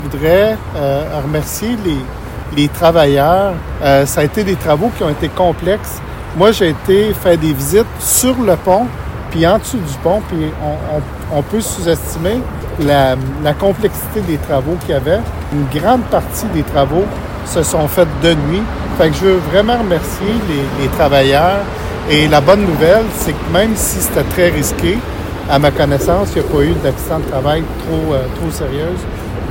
Lors d’une conférence de presse jeudi après-midi, Québec a annoncé que le remplacement de la dalle centrale du pont Laviolette est enfin terminé.
Présent à la conférence de presse, le ministre de l’Agriculture, des Pêcheries et de l’Alimentation et ministre responsable de la région du Centre-du-Québec, Donald Martel, a évoqué la complexité des travaux et les obstacles rencontrés par les travailleurs sur le chantier.